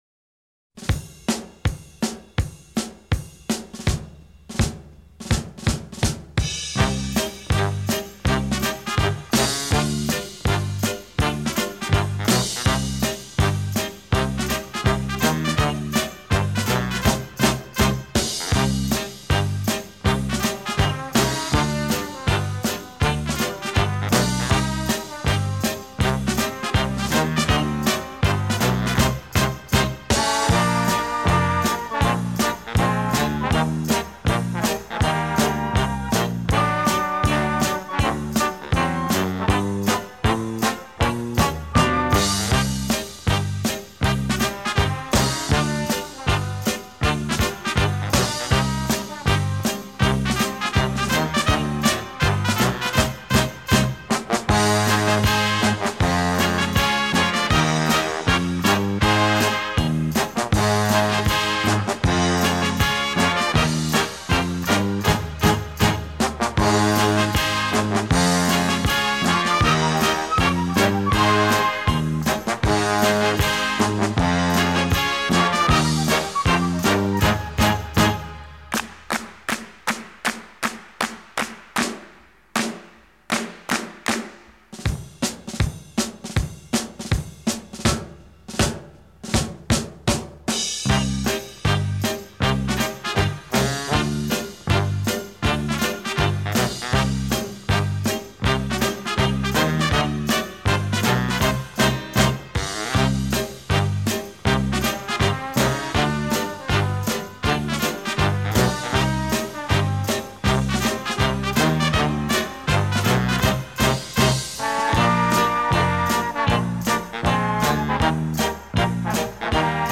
德国录制1973 韩国出版[银芯CD盘]1986